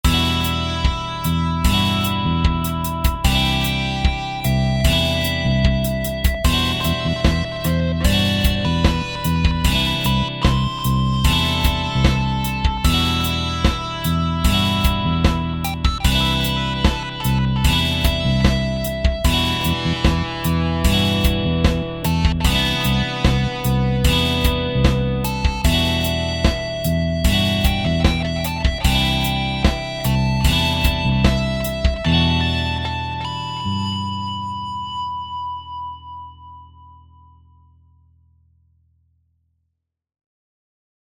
These were all made using ManyGuitar: there has been no external processing (except a touch of compression/limiting).
Jazz chords
ManyGuitar_Cadd9_Em7.mp3